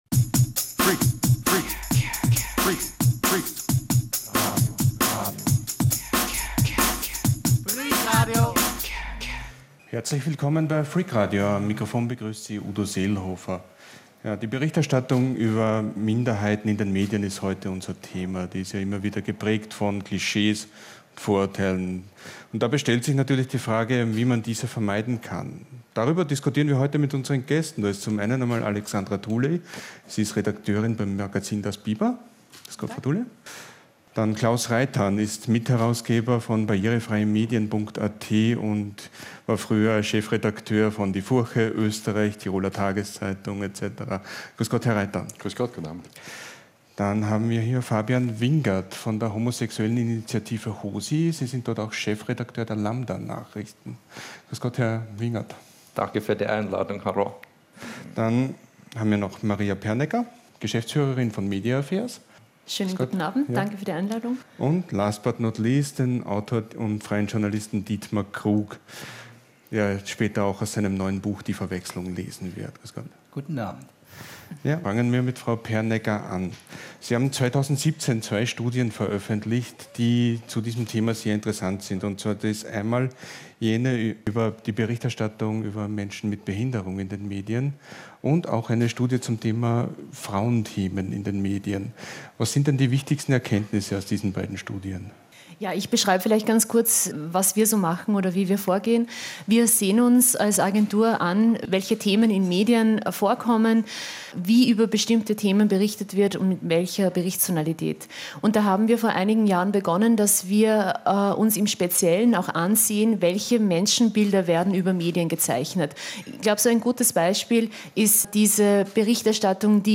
Wie sieht es mit der Wortwahl bezüglich Frauen oder Flüchtlinge aus und wie kann eine zeitgemäße politisch korrekte Berichterstattung über sogenannte Randgruppen aussehen? Welche Verantwortung haben Medien in diesem Zusammenhang? Über Sinn und Unsinn politischer Korrektheit diskutieren wir mit Experten und Betroffenen.